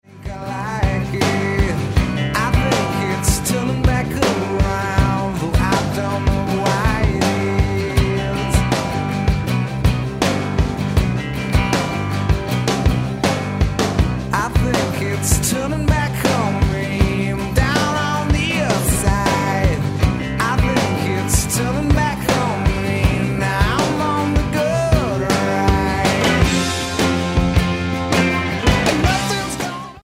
guitar
drums
bass